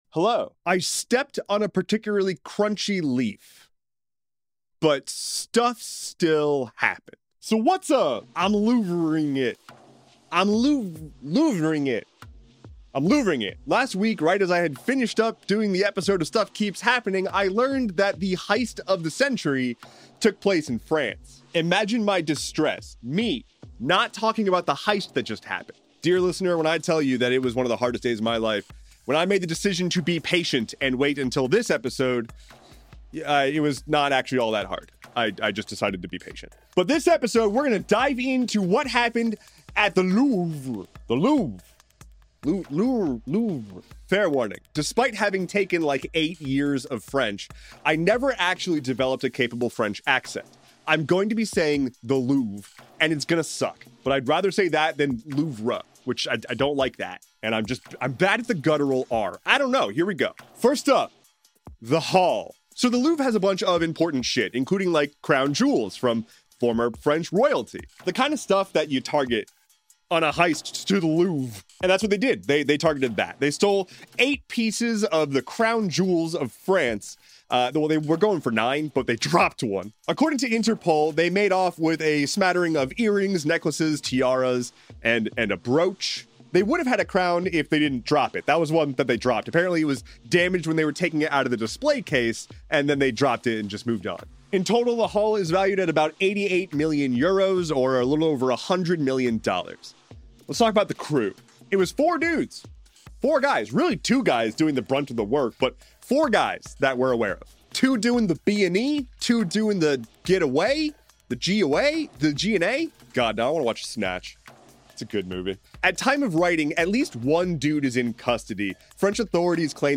A rambling recap of just what the heck is going on.